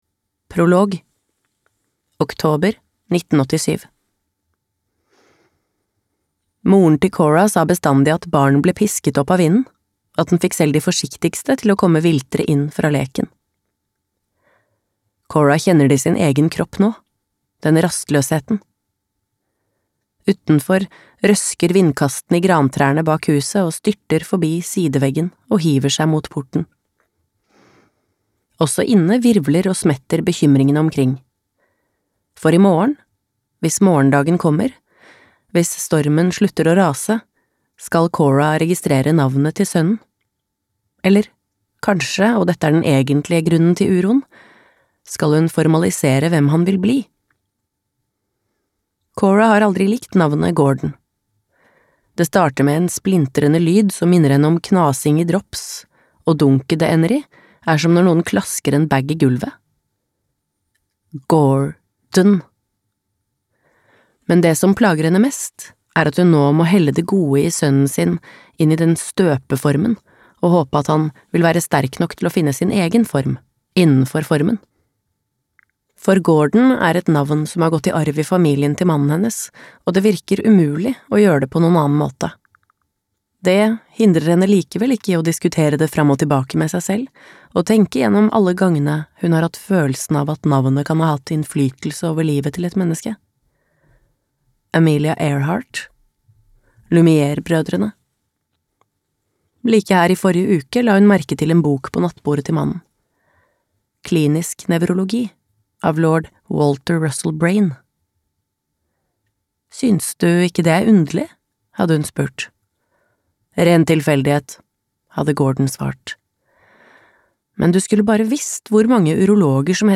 Navnene (lydbok) av Florence Knapp